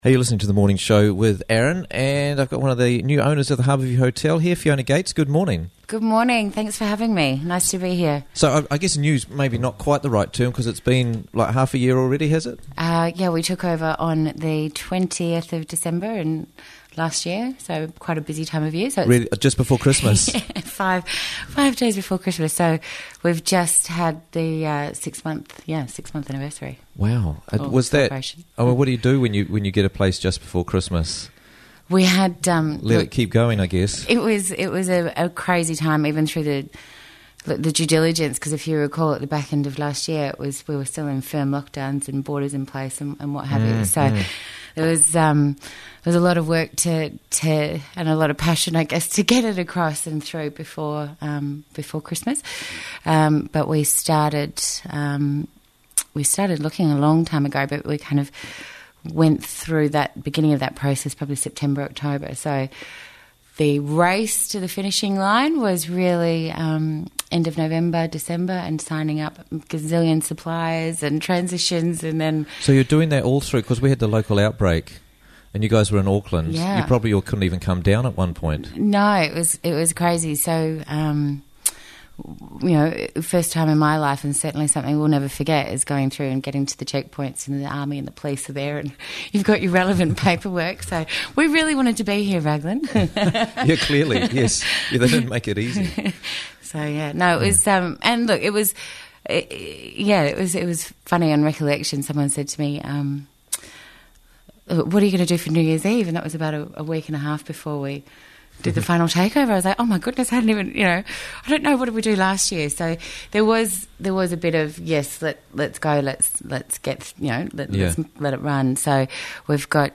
Interviews from the Raglan Morning Show